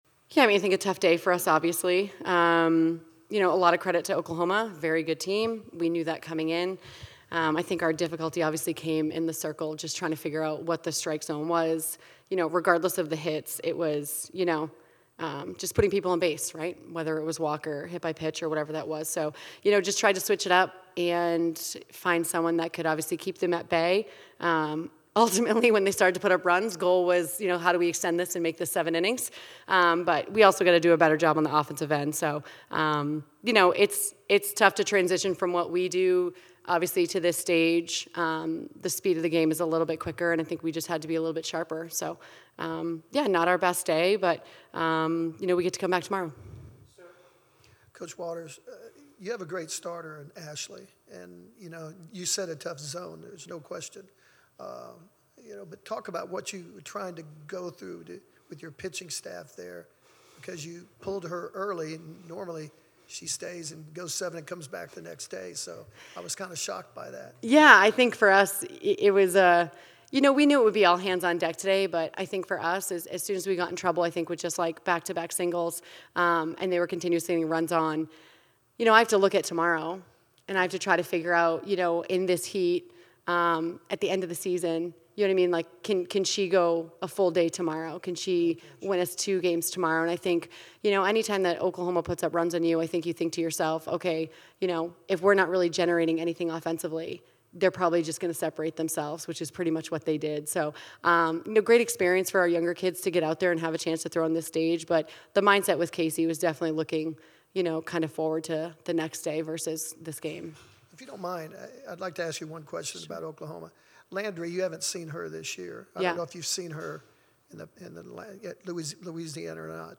Softball / Oklahoma Postgame Interview (5-16-25)